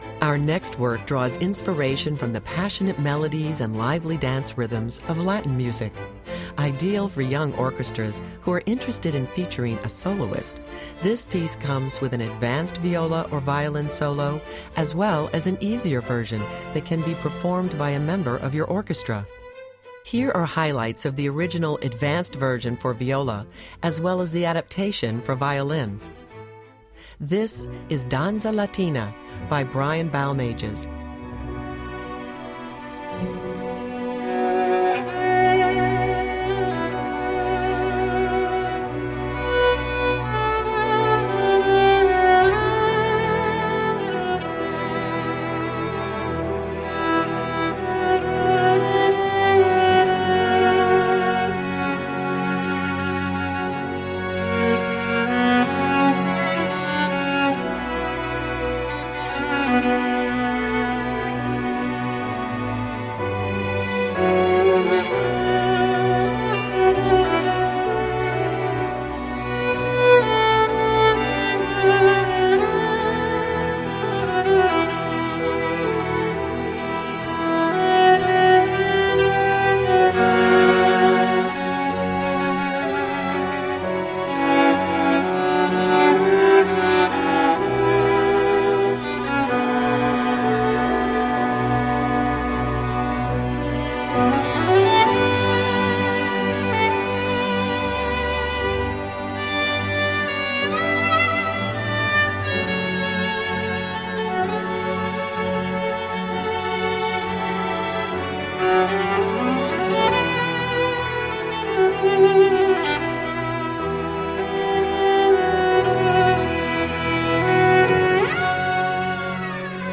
for Solo Viola or Violin and Orchestra
Voicing: String Orchestra